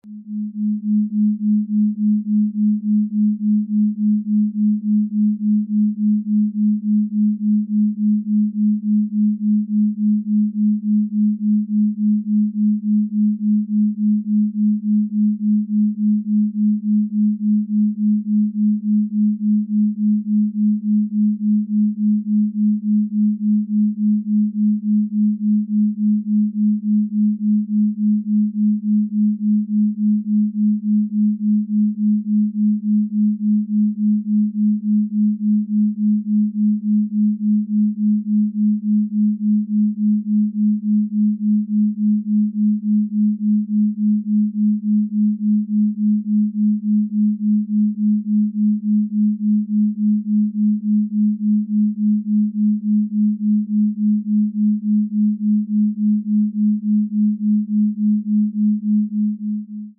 The audio frequency samples below were recorded with the app.
209 Hz & 212.5 Hz : 3.5 Hz beat frequency (Delta)
Binaural beat.